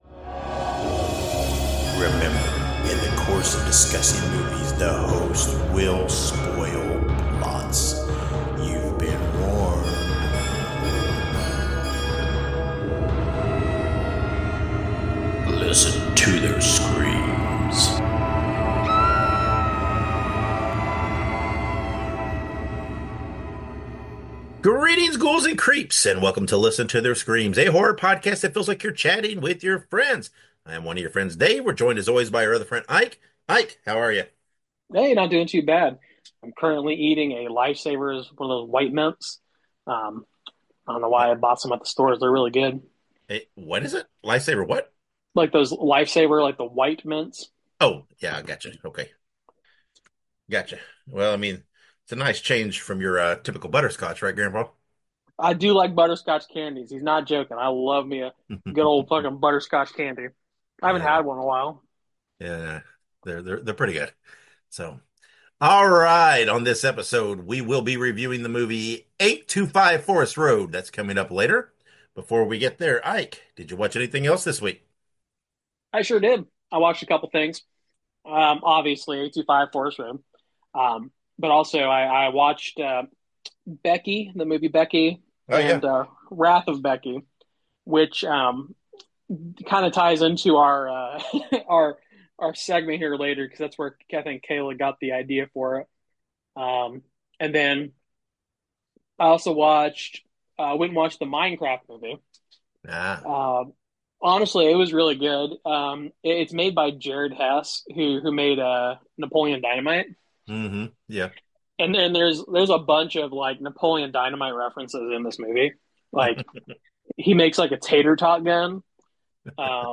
Horror podcast that feels like you’re chatting with friends! Includes movie reviews, horror news, and games.